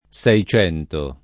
SHi©$nto] (tosc. o lett. secento [Se©$nto]) num. — il S. o il s., il secolo XVII; assai com. in questo sign., e più ancóra nei der. -esco, -ismo, -ista, -istico, e nel comp. secentenario, la forma secento